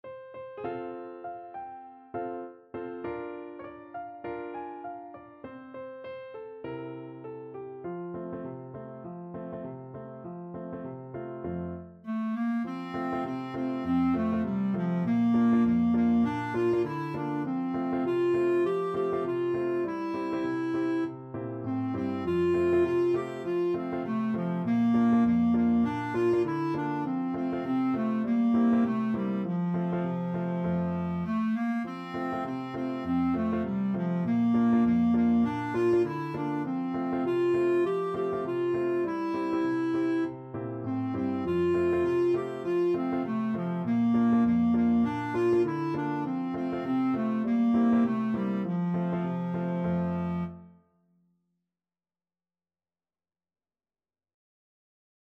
Clarinet
F major (Sounding Pitch) G major (Clarinet in Bb) (View more F major Music for Clarinet )
Swing 16, =100
Traditional (View more Traditional Clarinet Music)